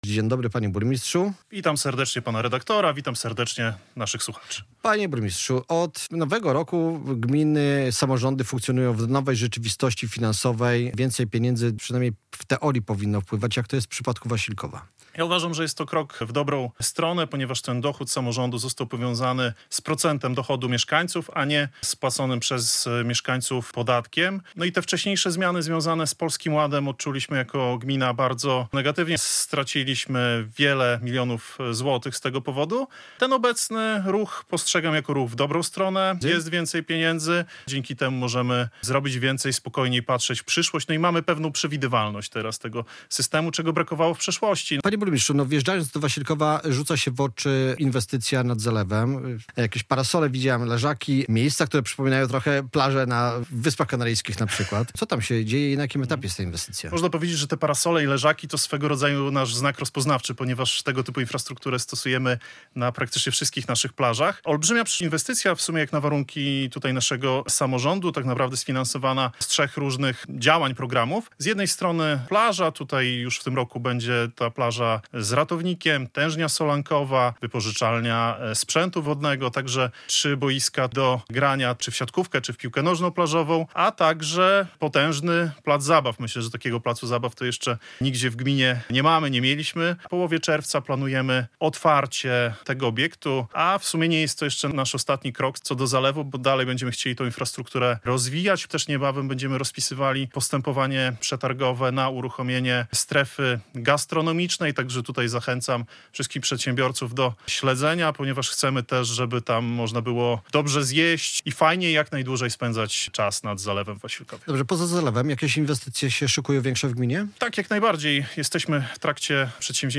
Z burmistrzem Wasilkowa Adrianem Łuckiewiczem rozmawia